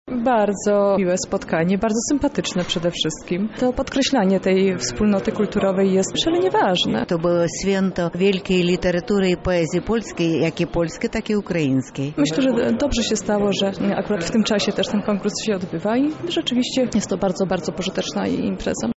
A co o przebiegu konkursu mówią widzowie?